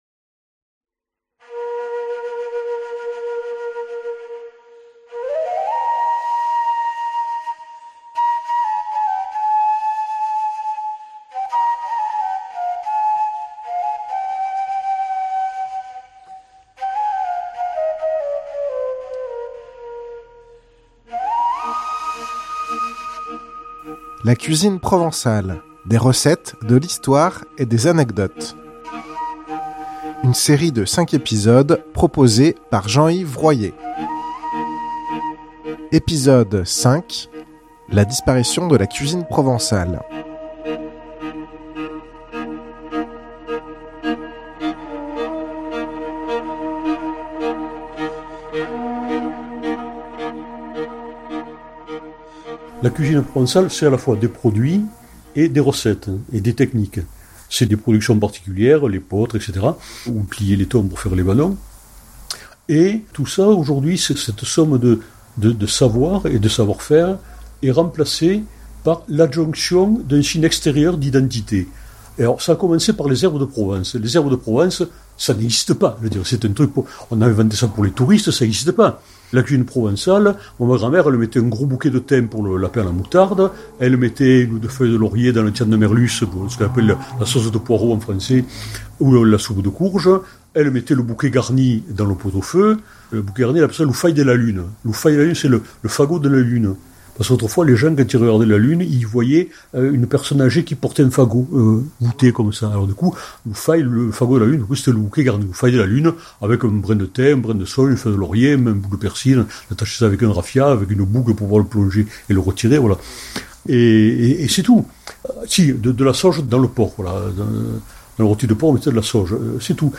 Episode 5 : la disparition de la cuisine provençale Reportage